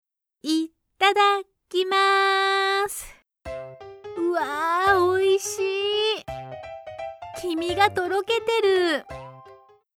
Character Samples
Young Girl
(8-16 yo)